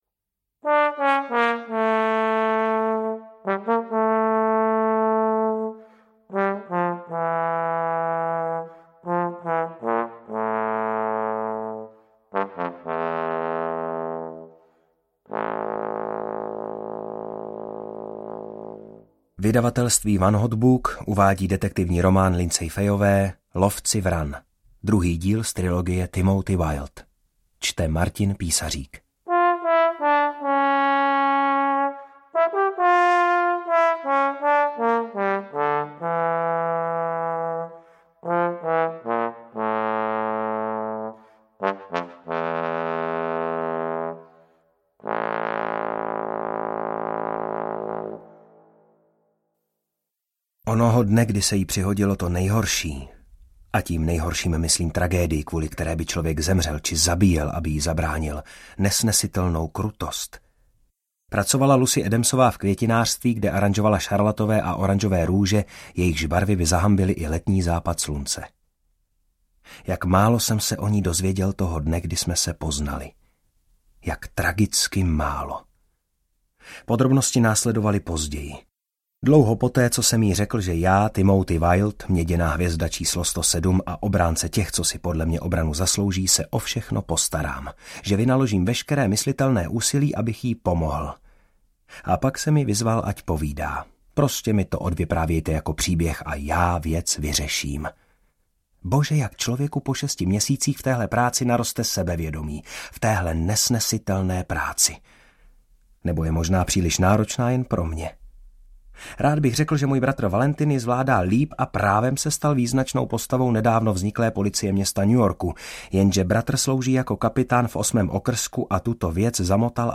Ukázka z knihy
lovci-vran-audiokniha